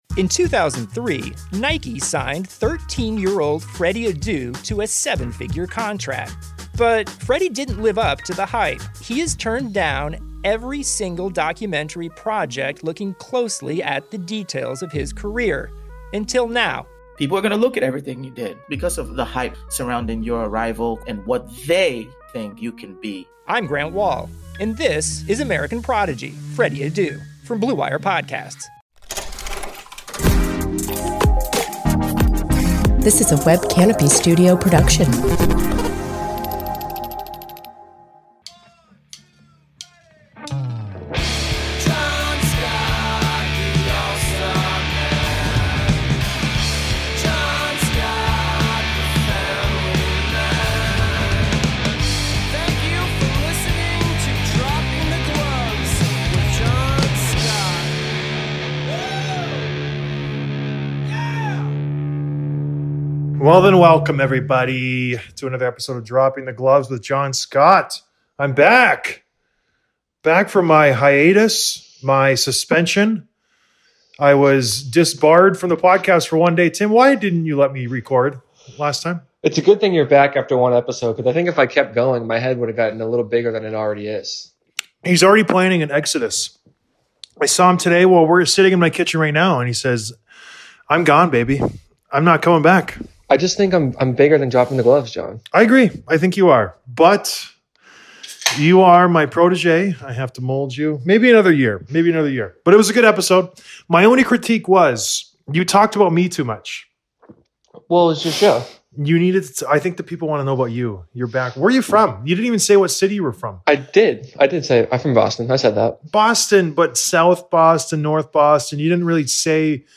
Reviewing the Reverse Retro Jerseys + Denis Savard Interview